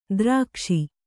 ♪ drākṣi